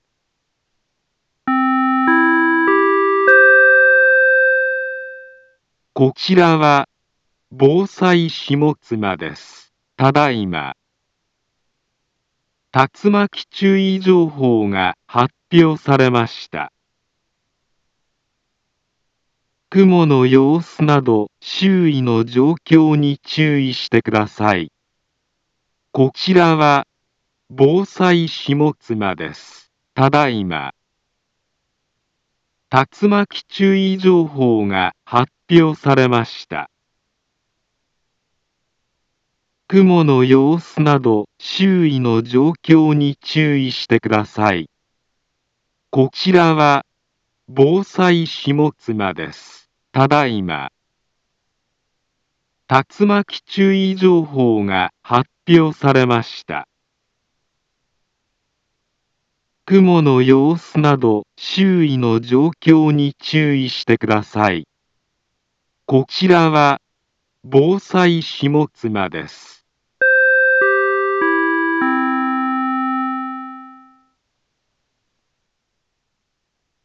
Back Home Ｊアラート情報 音声放送 再生 災害情報 カテゴリ：J-ALERT 登録日時：2022-06-03 13:29:53 インフォメーション：茨城県北部、南部は、竜巻などの激しい突風が発生しやすい気象状況になっています。